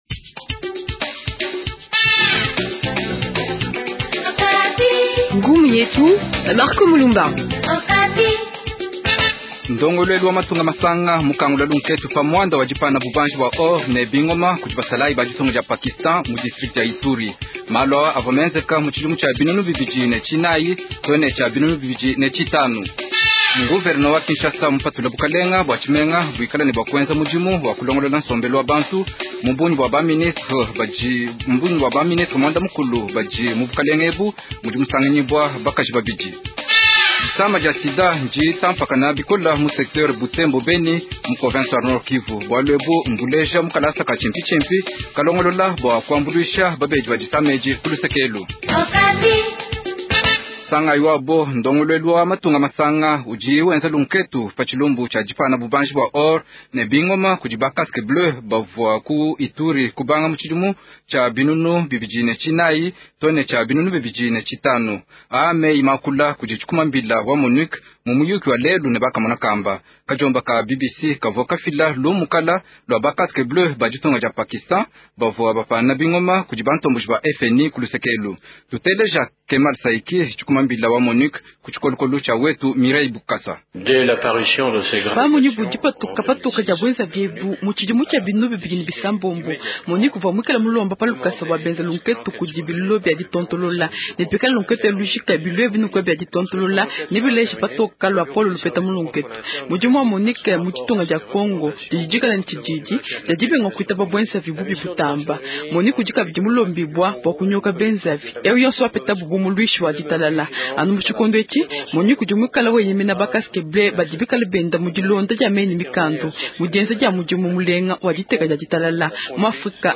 Journal Tshiluba Soir
André Kimbuta, vous allez l’entendre, s’en défend, il y avait des urgences qu’il fallait intervenir. Titre 3 : Des cas de personne atteinte du VIH Sida augmente dans le secteur Butembo-Beni.